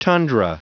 Prononciation du mot tundra en anglais (fichier audio)
tundra.wav